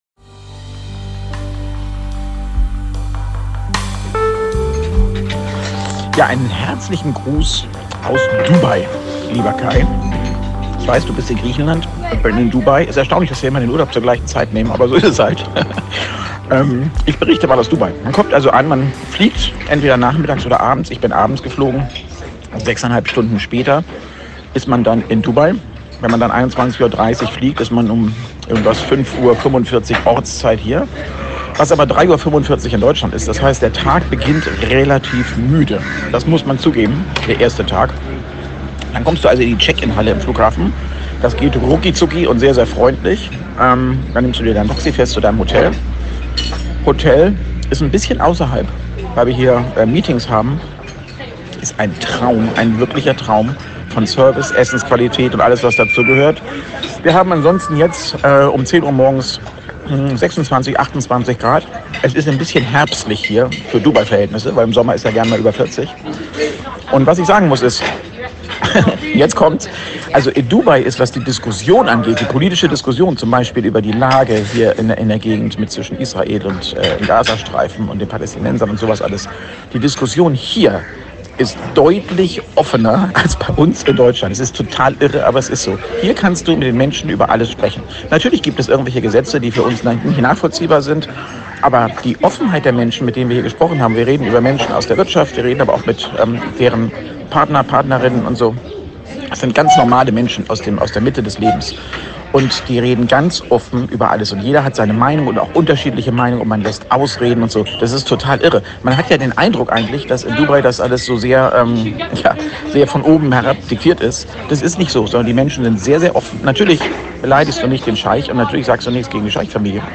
Schon 50 Folgen, pünktlich zu dieser Folge sind beide unterwegs aber sie lassen es sich nicht nehmen, sich von unterwegs hören zu lassen.